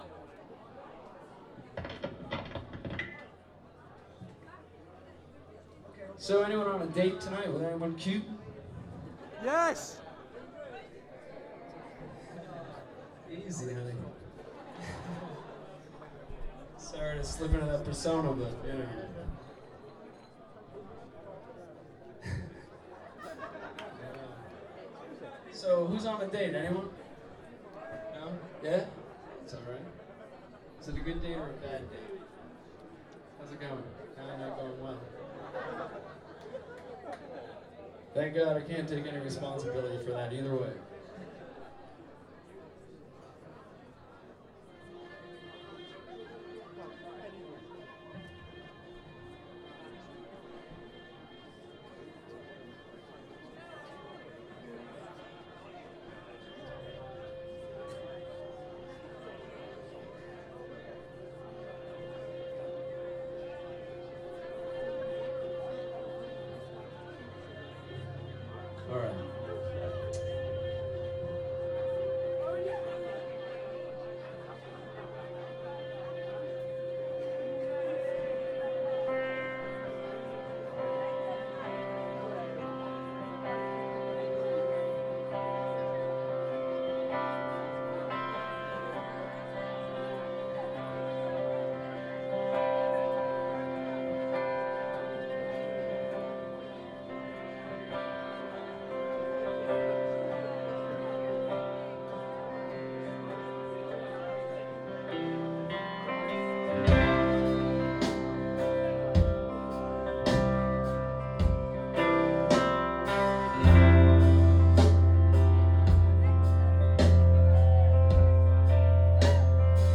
highbury garage london june 29 2000